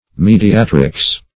Mediatrix \Me`di*a*"trix\